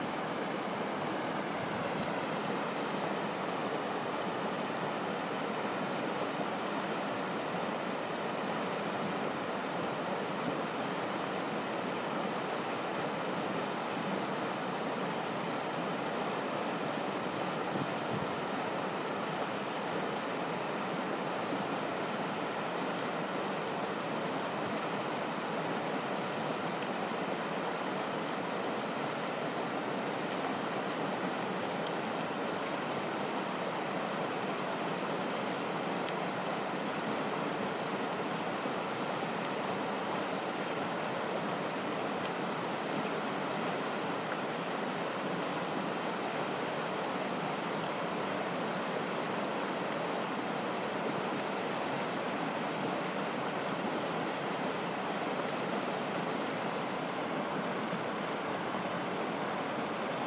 mountain river in Kyrgyzstan